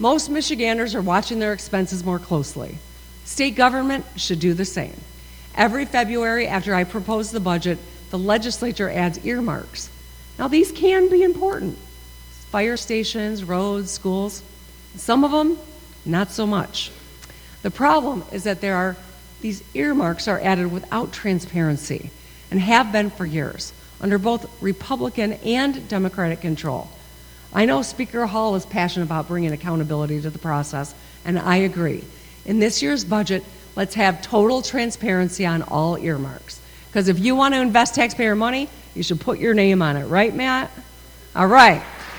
She delivered her 7th State of the State Address Wednesday night before a joint session of the Michigan House and Senate.